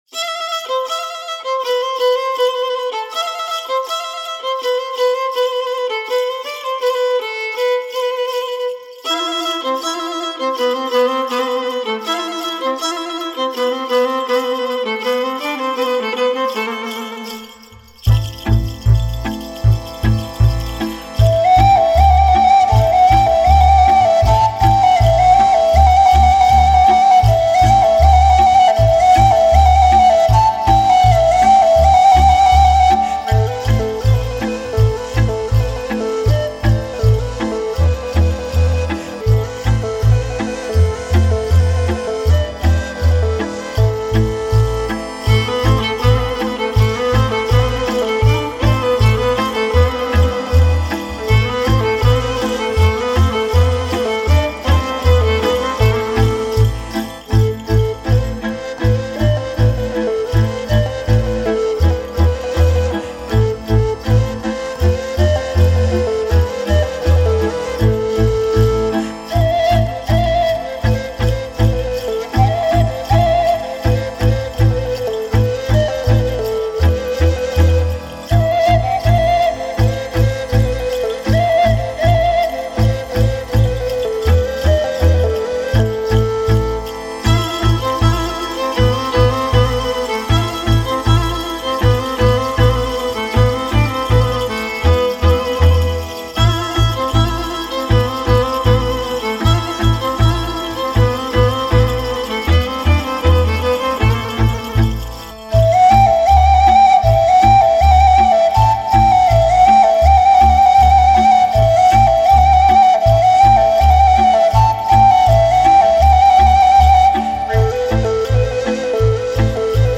Joc ciobănesc din caval (3:11)